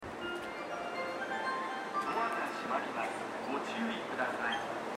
スピーカーはＴＯＡ型が設置されており音質も高音質です。
発車メロディーフルコーラスです。